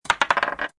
dice-roll-free-sound-effect.mp3